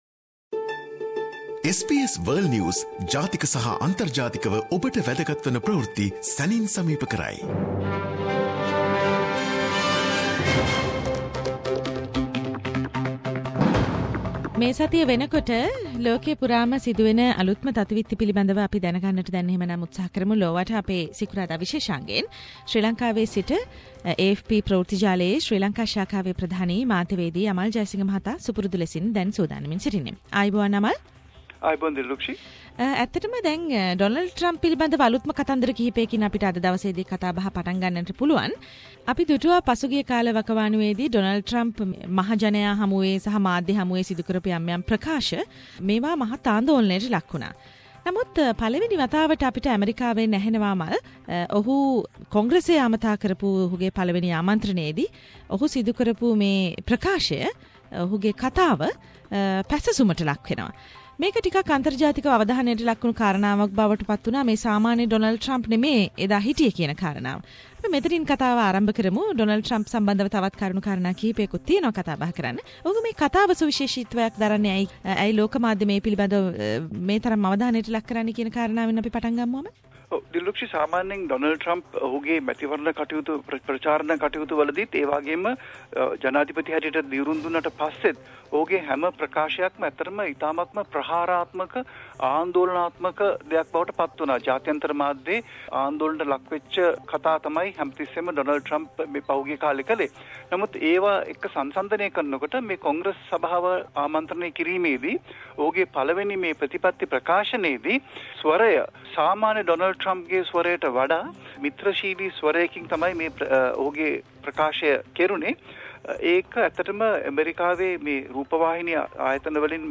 Donald Trump’s name has been nominated to the 2017 Nobel peace prize by an unidentified American – SBS Sinhalese “Around the World”: weekly world news wrap